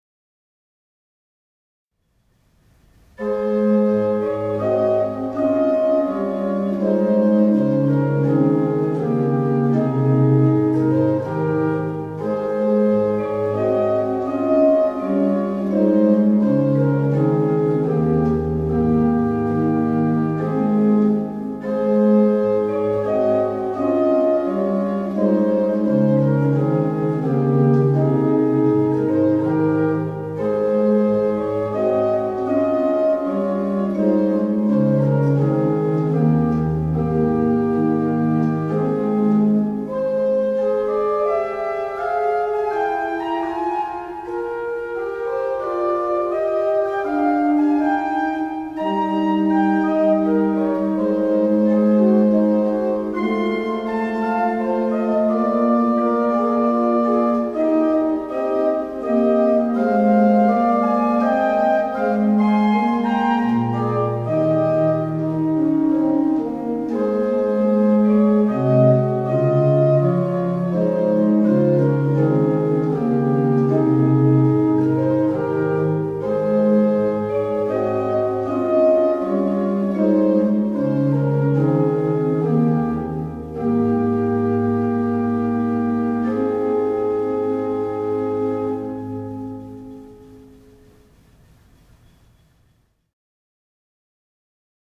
Orgelmuziek voor het hele jaar